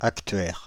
Ääntäminen
Ääntäminen France (Île-de-France): IPA: /ak.tɥɛʁ/ Paris: IPA: [ak.tɥɛʁ] Haettu sana löytyi näillä lähdekielillä: ranska Käännös 1. αναλογιστής {m} (analogistís) Suku: m .